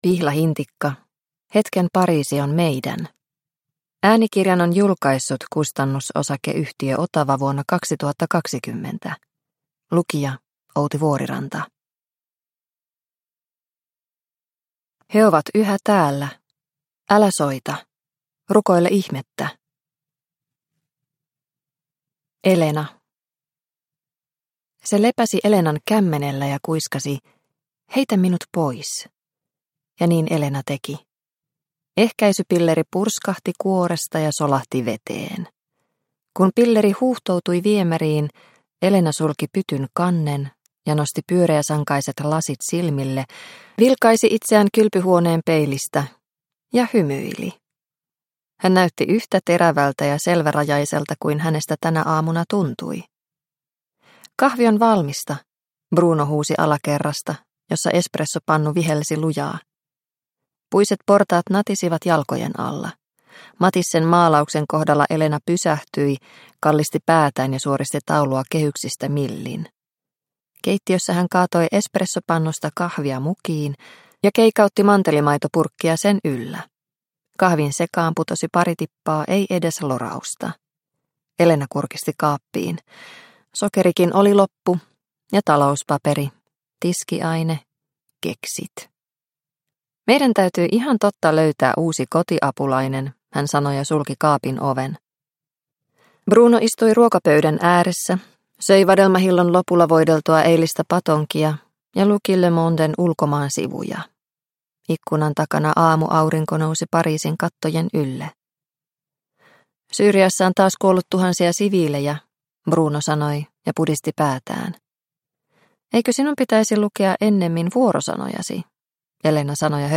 Hetken Pariisi on meidän – Ljudbok – Laddas ner